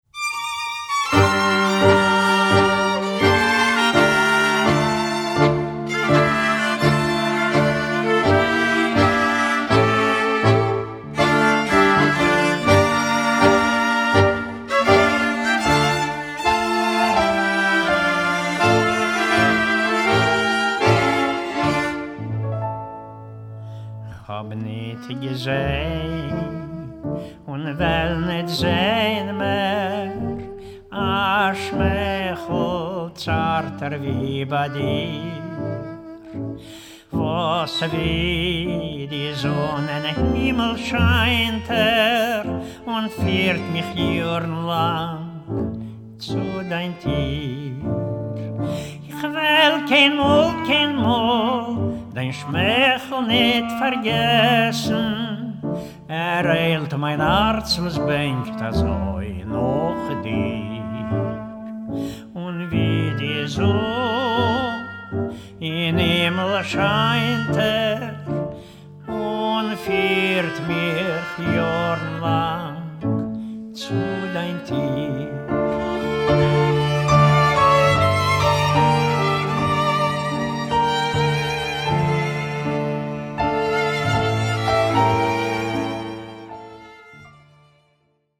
A self-taught, natural lyrical tenor
Genres: Yiddish, World.
vocals
viola, violin
violoncello
piano, accordion
clarinet, alto saxophone
trumpet
mandolin
guitar
Recorded in Vienna, October 12-15, 2011